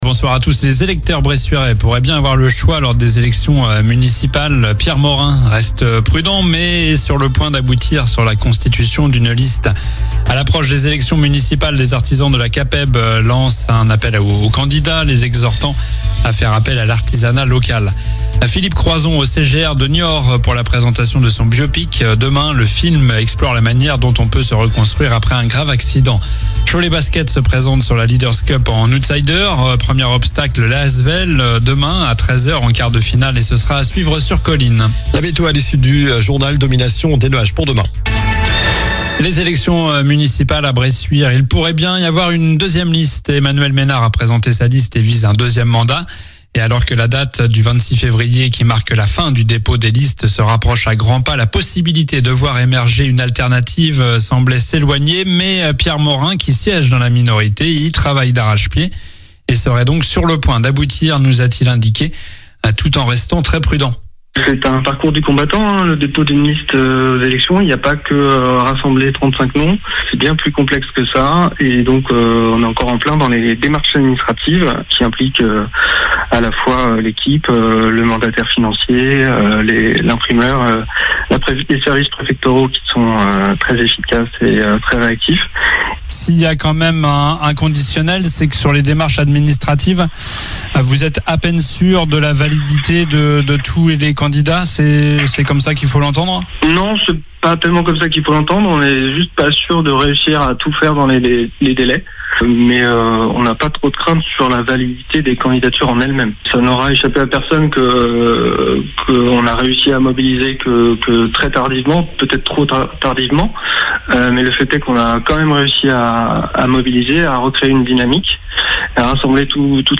Journal du jeudi 19 février (soir)